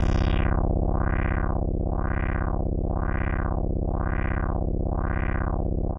Index of /90_sSampleCDs/Trance_Explosion_Vol1/Instrument Multi-samples/LFO Synth
C1_lfo_synth.wav